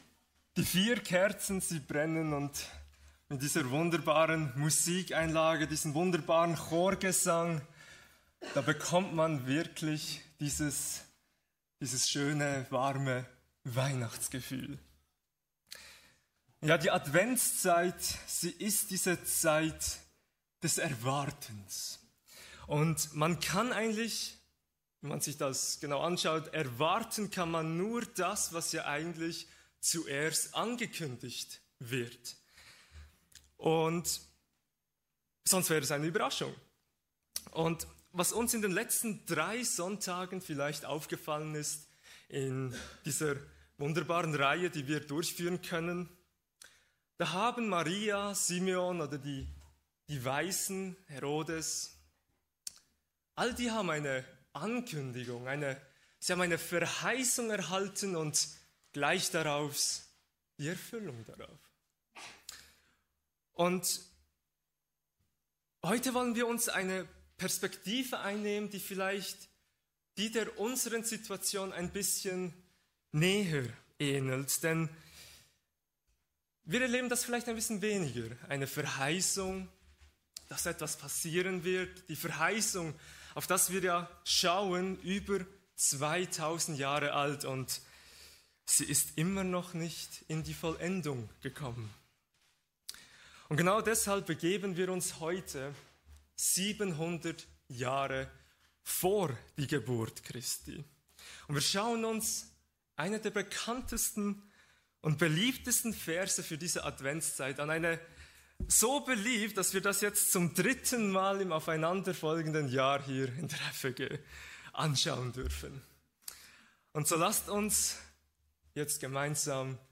Kategorie: Adventsgottesdienst